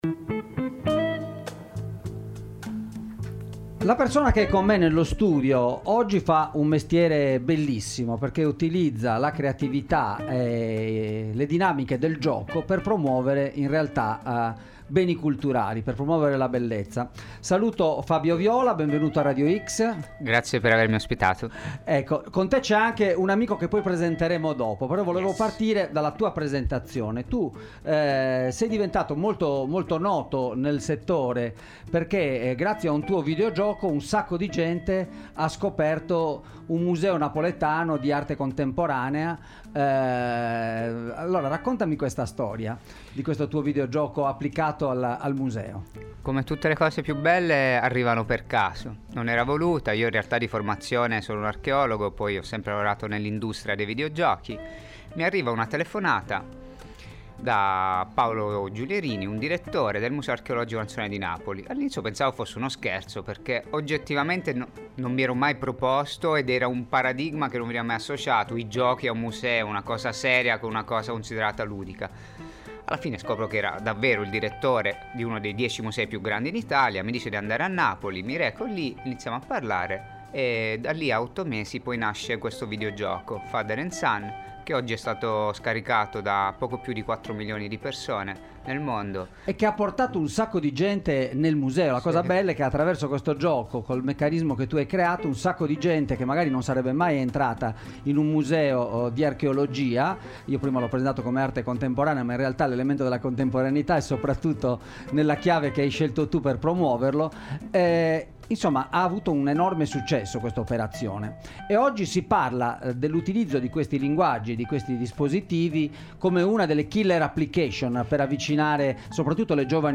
Se i videogiochi dialogano con la cultura – intervista